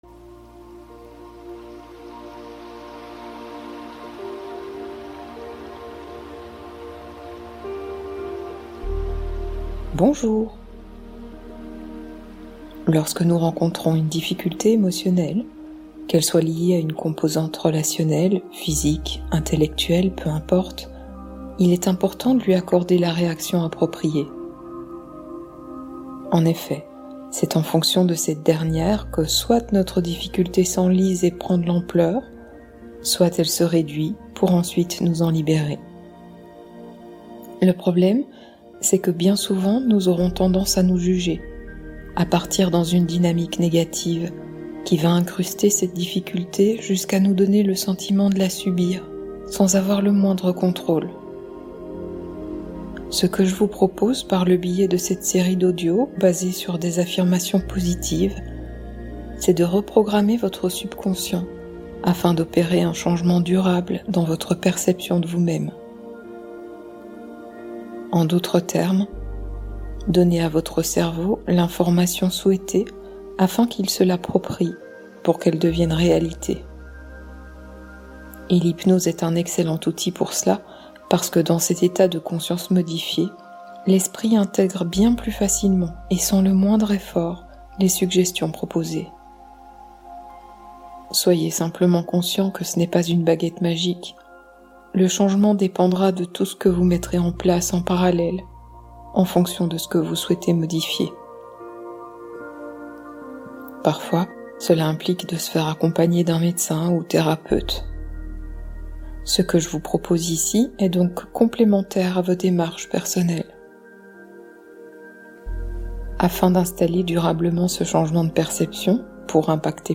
Méditation Guidée Du contrôle au lâcher-prise : la reprogrammation qui libère enfin votre esprit Oct 14 2025 | 00:14:15 1x 00:00 / 00:14:15 Subscribe Share